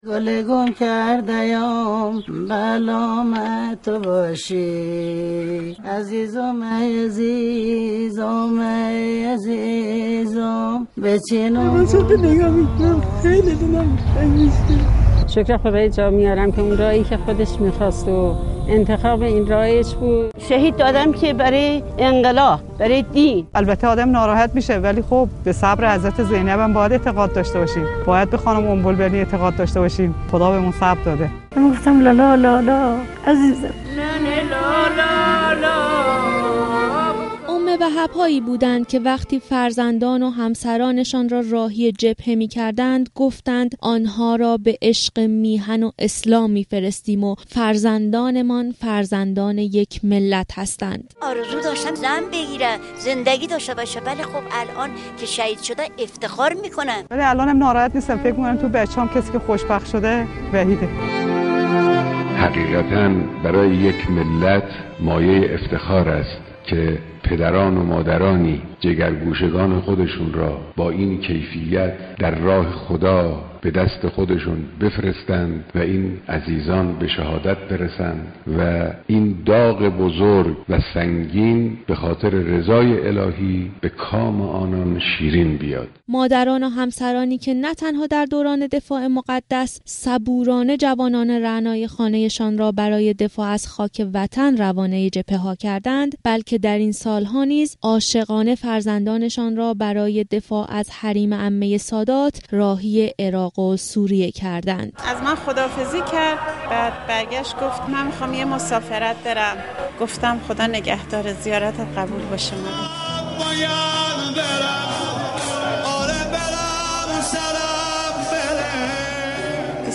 گزارش خبرنگار رادیو زیارت را در این زمینه بشنوید: